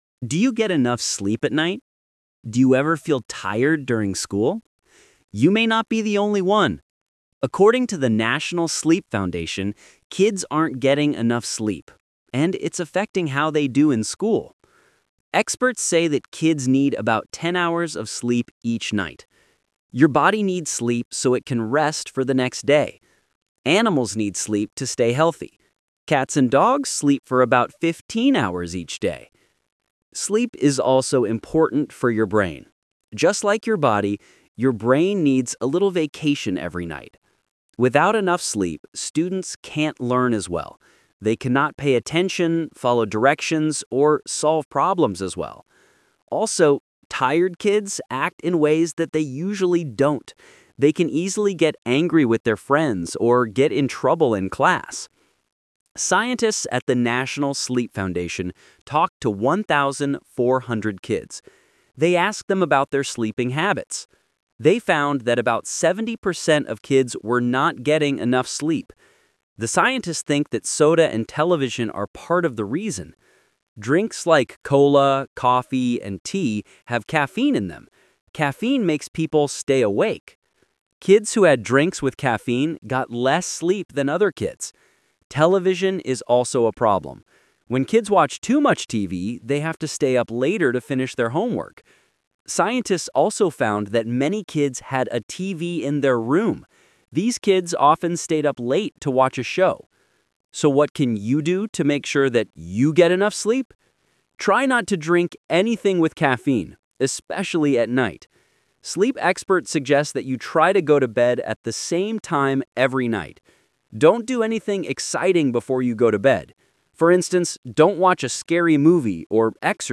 Talk/Lecture 2: Listen to a man talking about sleeping habits.